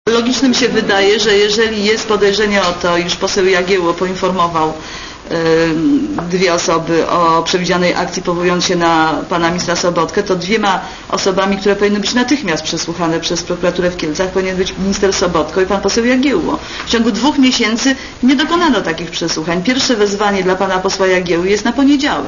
Komentarz audio (88Kb)